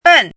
chinese-voice - 汉字语音库
ben4.mp3